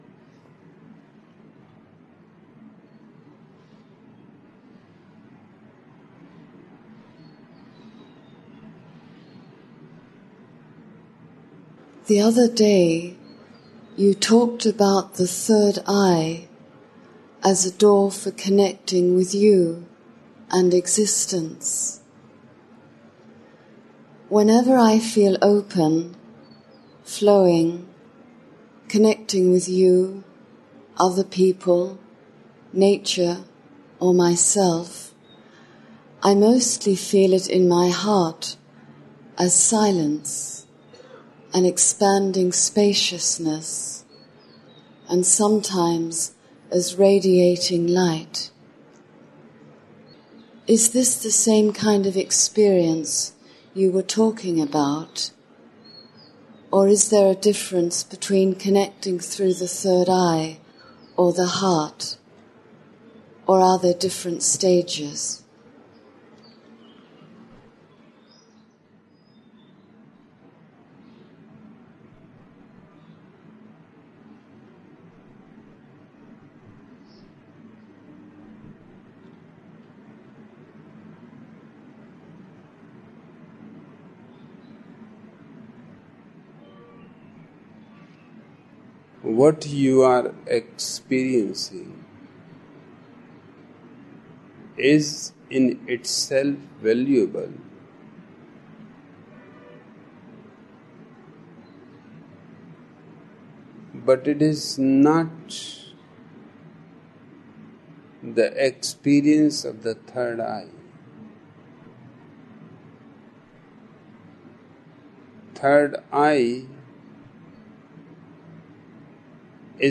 Each program has two parts, Listening Meditation (Osho discourse) and Satsang Meditation.
Throughout all of these discourses is a common thread and that is Osho’s teaching of witnessing. The Listening Meditations in the following programs include discourses given by Osho from 1974 – 1988 and were given in Pune, India; Rajneeeshpuram, OR; Kathmandu, Nepal; Punte del Este, Uruguay; Mumbai and Pune, India.